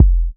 • Clean Urban Bass Drum Sound F Key 180.wav
Royality free kickdrum sound tuned to the F note. Loudest frequency: 78Hz
clean-urban-bass-drum-sound-f-key-180-swN.wav